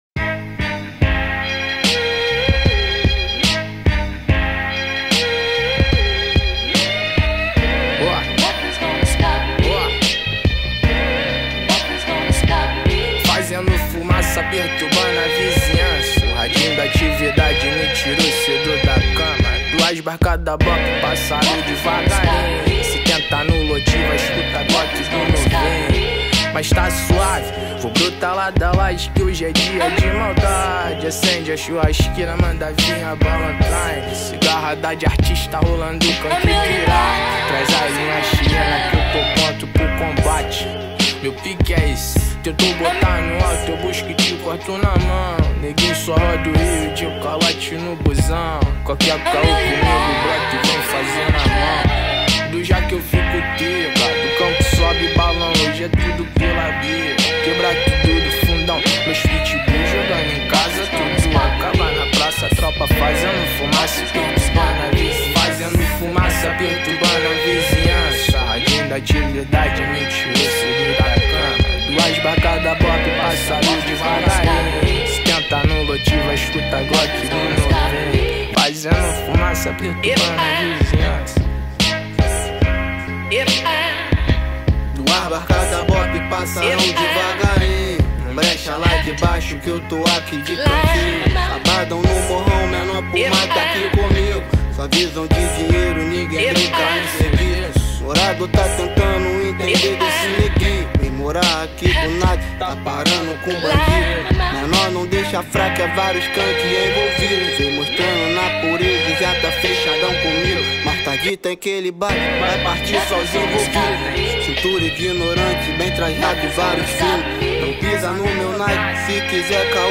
2024-04-29 20:53:17 Gênero: Rap Views